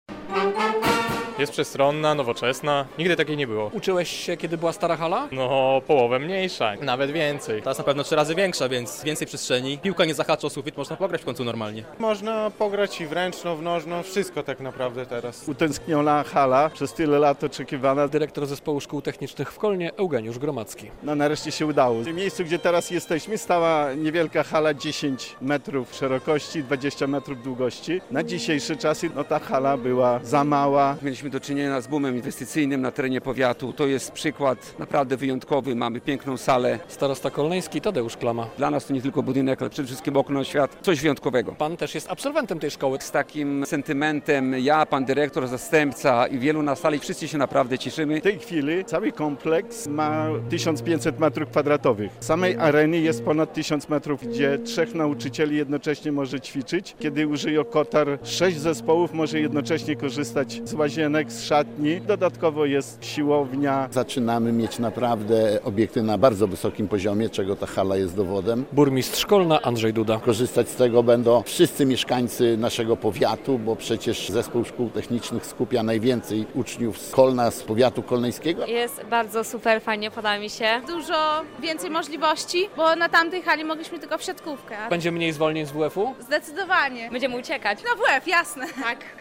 W Zespole Szkół Technicznych w Kolnie oddano w piątek (3.10) oficjalnie do użytku nową halę sportową.